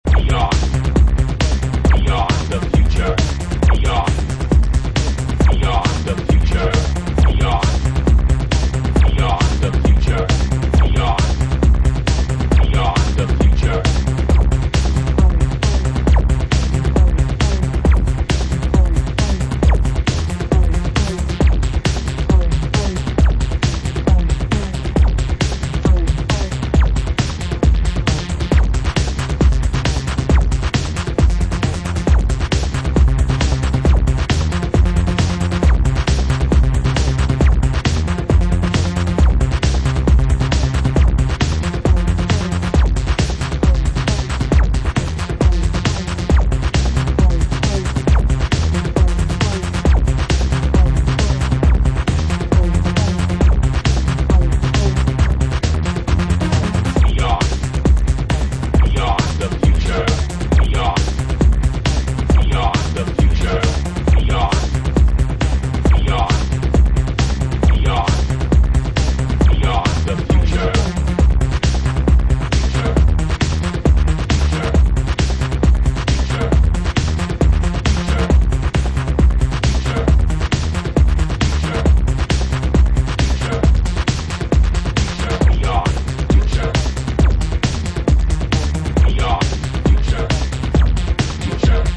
electro/techno